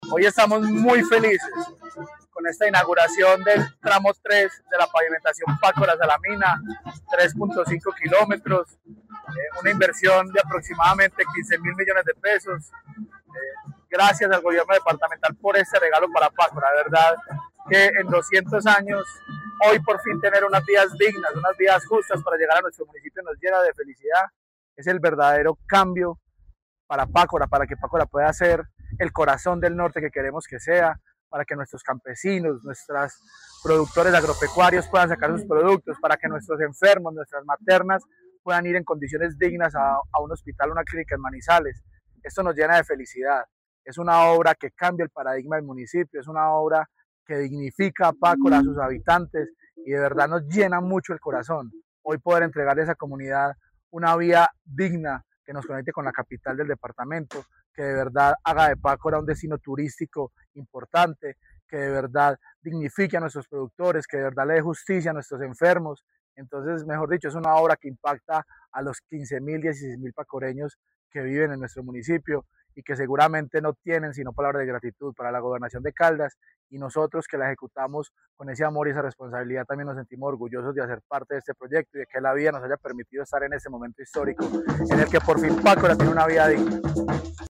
Juan Camilo Isaza González, alcalde de Pácora